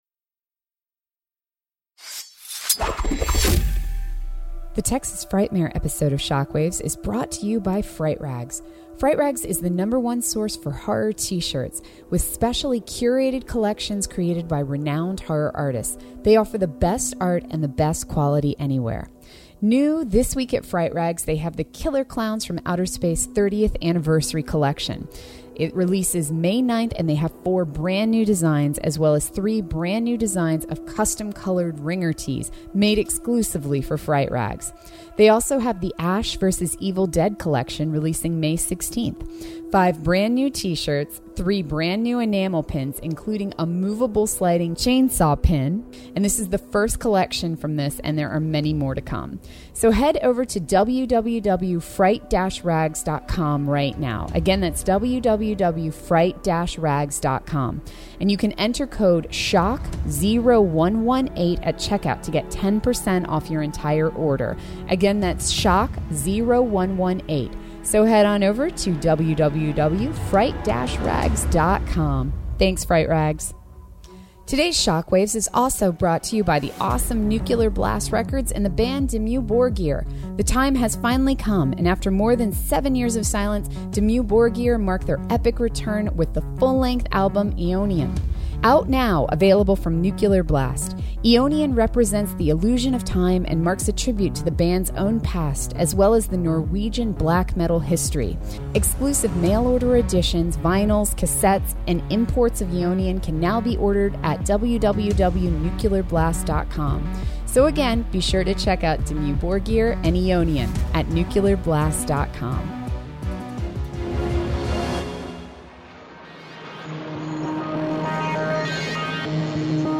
Yes, this is our LIVE episode from Texas Frightmare Weekend in Dallas! Get ready for a wild, humorous discussion and get those pencils and notepad ready, you're going to want to take notes on the films mentioned here.